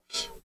100 MD CYM-R.wav